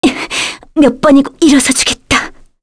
Scarlet-Vox_Dead_kr.wav